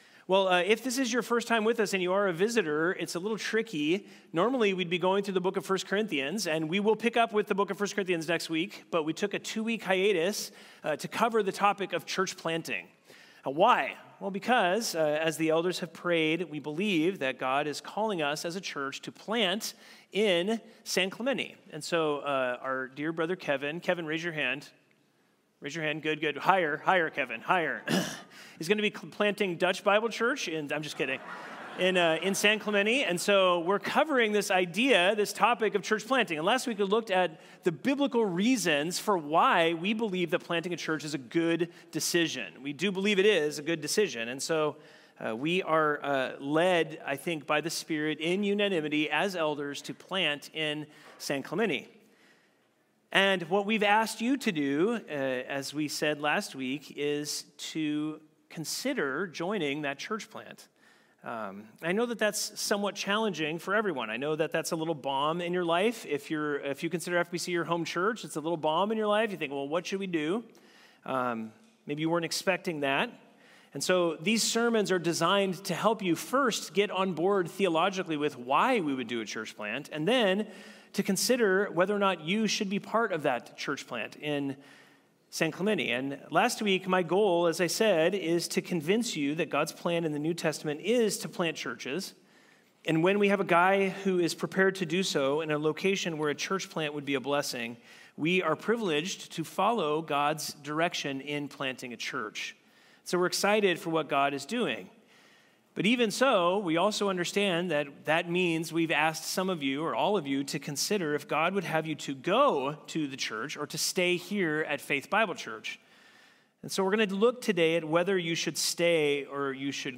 Faith+Bible+Church+June+1,+2025+Church+Service.mp3